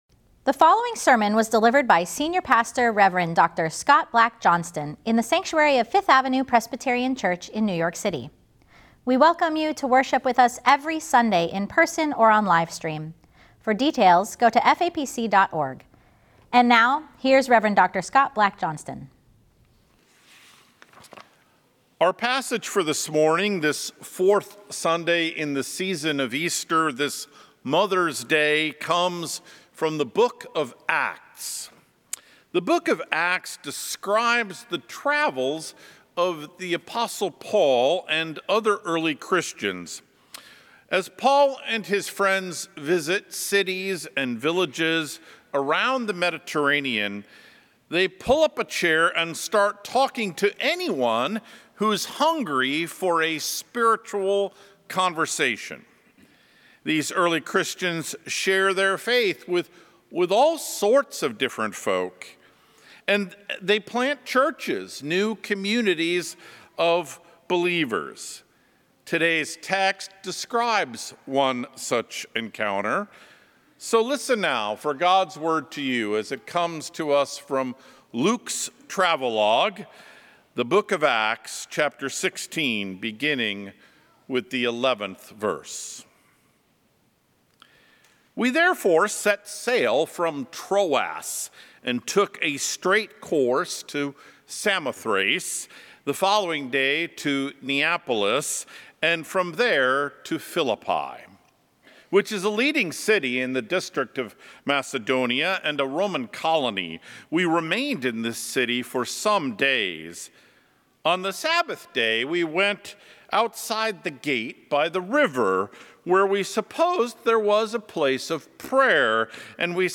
FAPC Sermon Series: